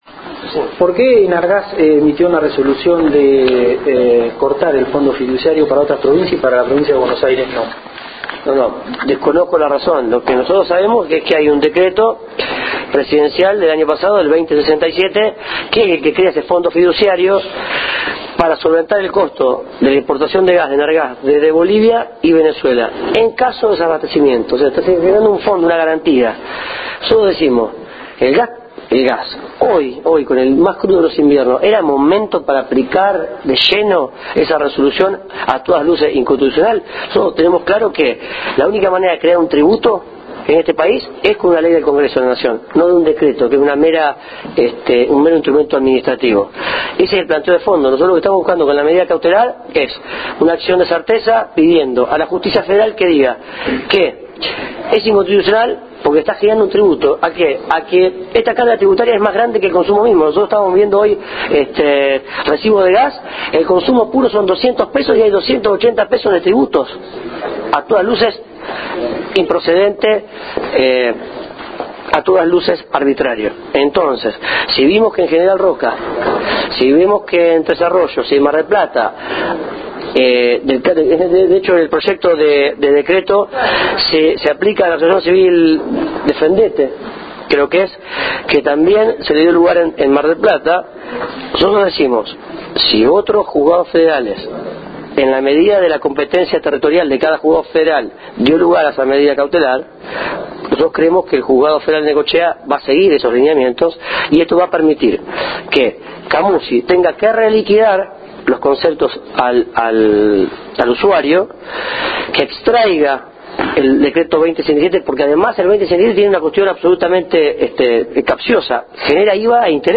Los Concejales  y Consejeros Escolares electos  de la Unión Pro estuvieron presentes en Juan N. Fernández, en el marco de una reunión partidaria y también donde se escuchó los reclamos de la gente.
Escuchar audio de Pablo Aued hablando del tema del gas